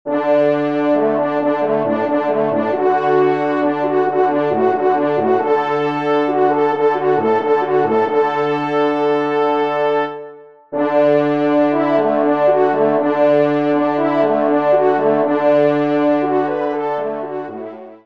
Genre :  Divertissement pour Trompes ou Cors
Pupitre Basse  Cor